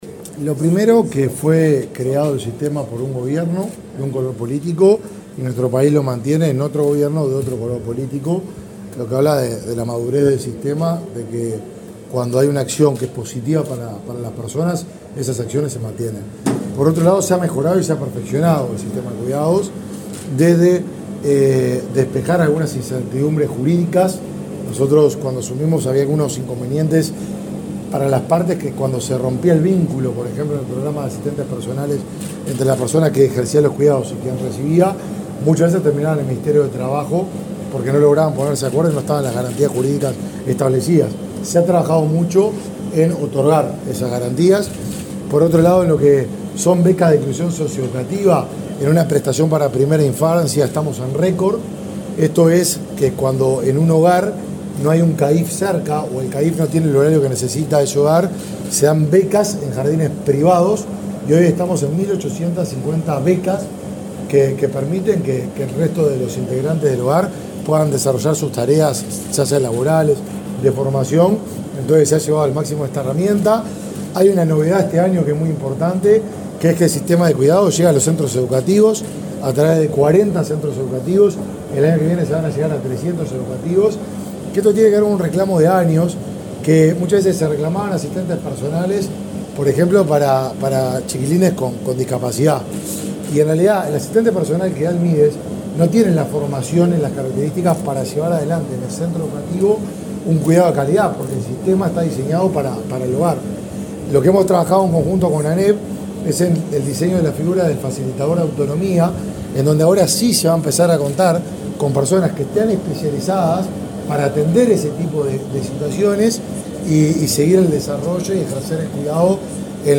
Declaraciones del ministro de Desarrollo Social, Martín Lema
Declaraciones del ministro de Desarrollo Social, Martín Lema 20/11/2023 Compartir Facebook X Copiar enlace WhatsApp LinkedIn Uruguay recibe esta semana a delegaciones de República Dominicana, Costa Rica, México, Cuba, Paraguay y de las oficinas del Fondo de Población de las Naciones Unidas, para intercambiar experiencias concretas sobre servicios y políticas públicas de cuidados. El ministro de Desarrollo Social, Martín Lema, participó, este lunes 20 en Montevideo, de la apertura de la jornada. Luego, dialogó con la prensa.